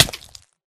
Sound / Minecraft / damage / hurtflesh2